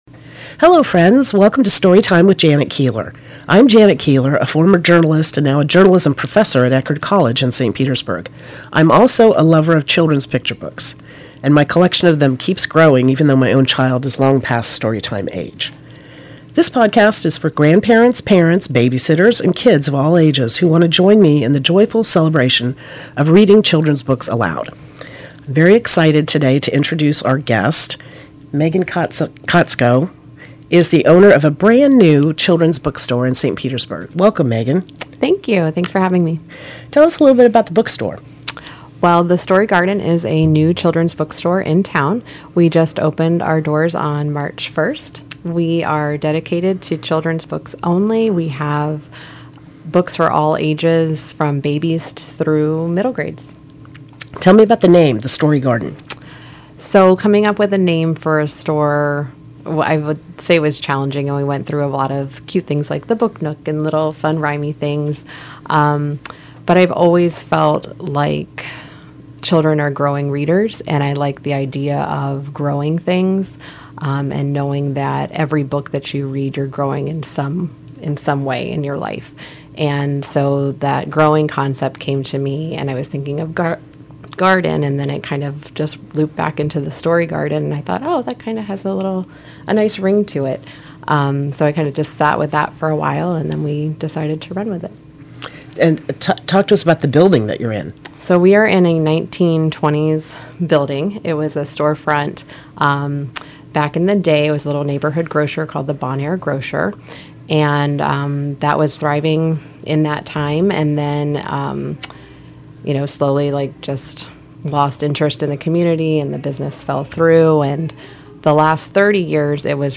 She reads one of our favorite books - Maurice Sendak's Where The Wild Things Are .